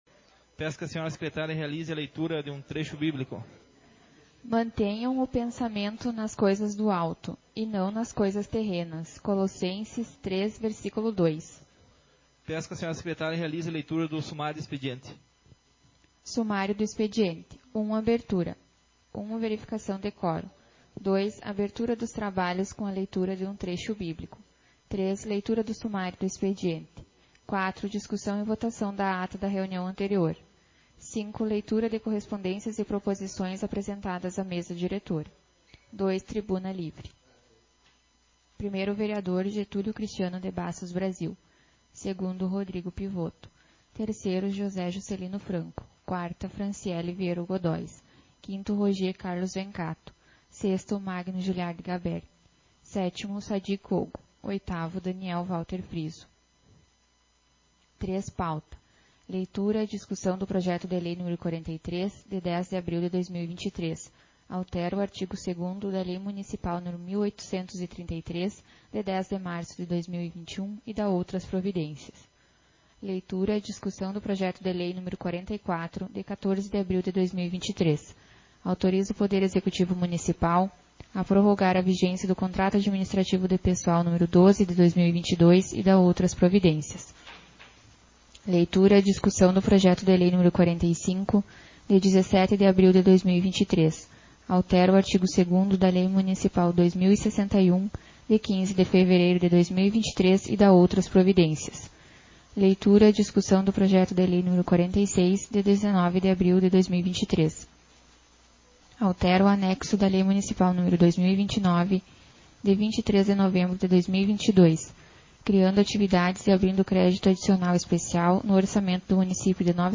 Sessão Ordinária 11/2023